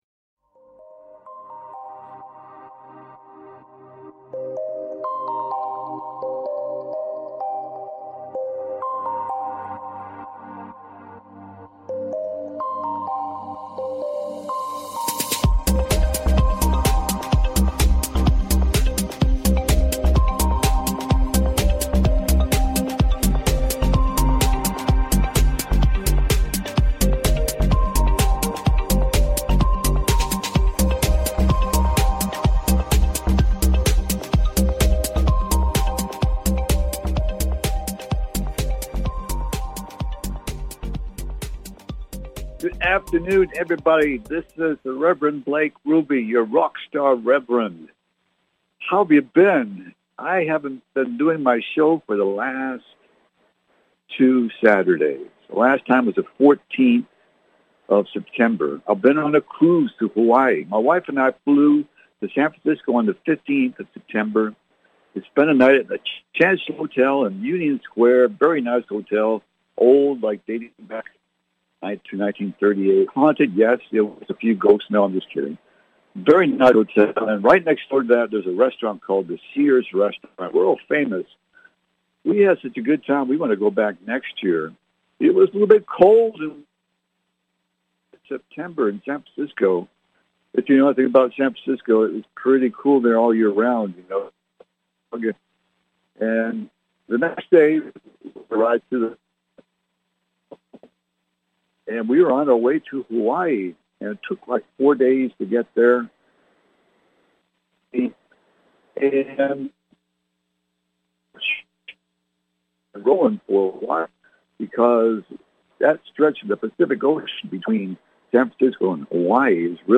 Talk Show Episode, Audio Podcast, The Church Of The Souls Evolution